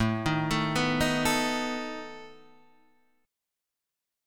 Asus2b5 chord {x x 7 8 10 7} chord